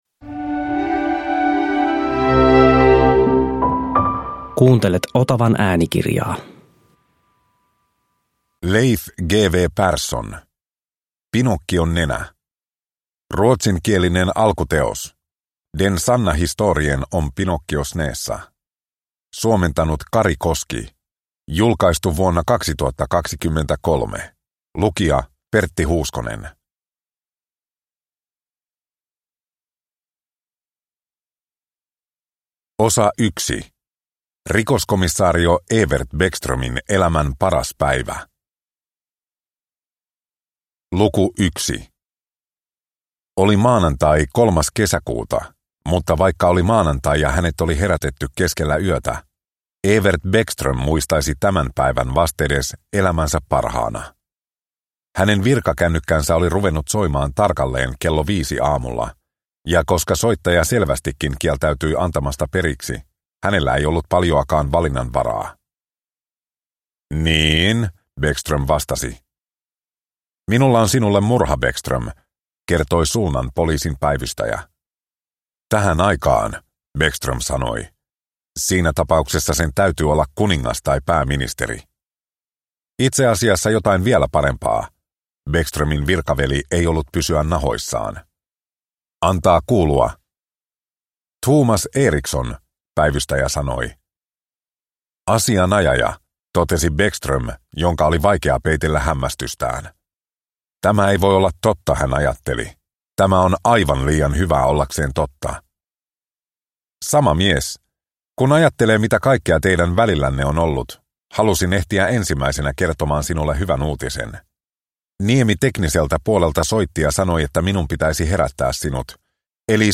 Pinokkion nenä – Ljudbok – Laddas ner